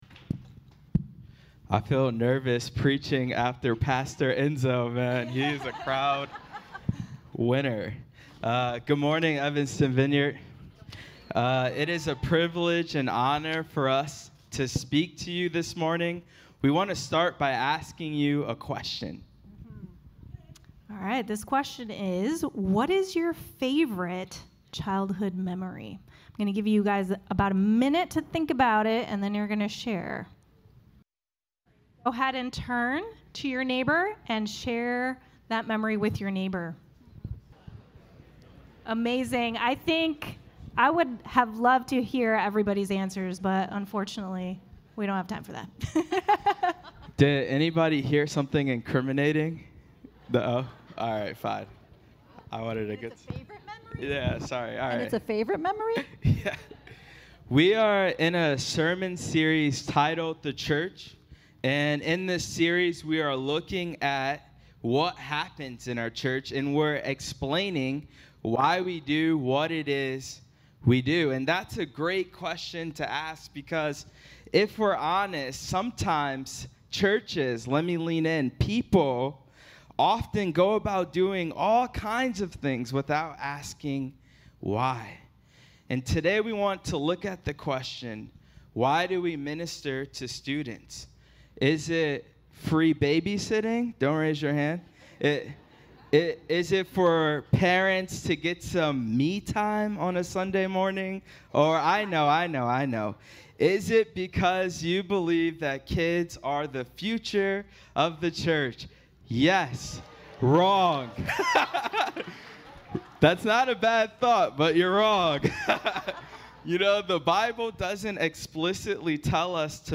This morning's message is brought by a special team of pastors!